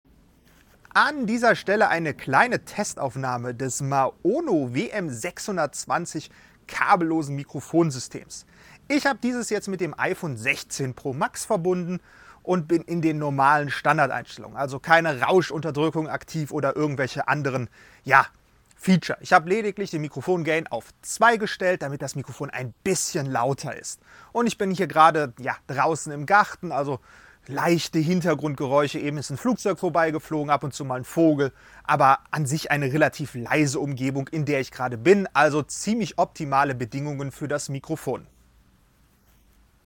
Ich habe die Mikrofonqualität des Maono WM620 an einem Apple iPhone 16 Pro Max getestet.
Ich habe lediglich den Gain am Empfänger etwas hochgestellt, da ansonsten der Ton für meinen Geschmack etwas leise war.
Generell liefert das Maono WM620 einen sehr klaren und sauberen Klang. Dieser könnte zwar etwas voluminöser sein, ist aber auf Sprachverständlichkeit zugeschnitten, die ebenfalls sehr gut ist.
So hören wir bei helleren und lauteren Geräuschen leichte Kompressionsgeräusche, die aber jetzt auch nicht extrem sind.
Maono-Testaufnahme.mp3